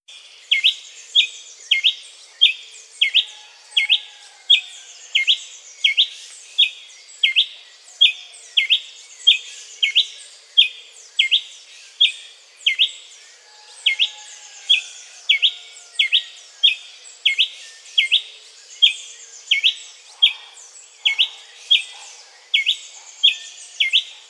Tachyphonus coronatus - Frutero coronado.wav